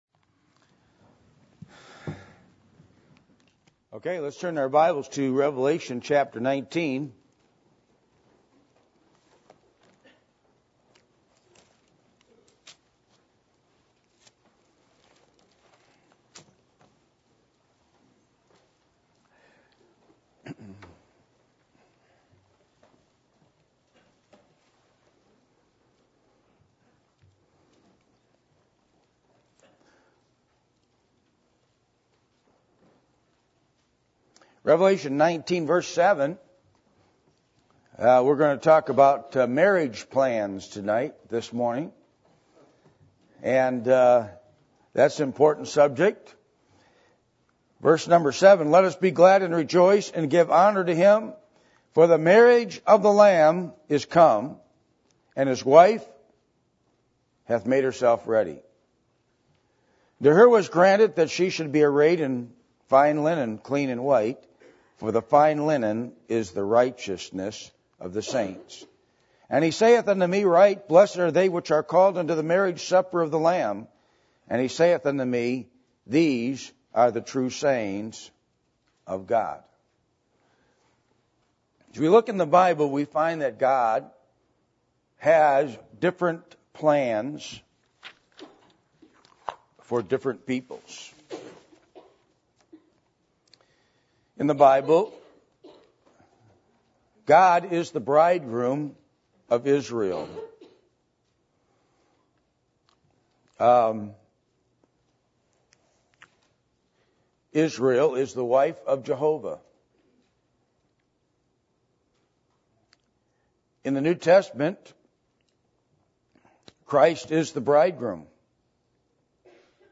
Passage: Revelation 19:7-9 Service Type: Sunday Morning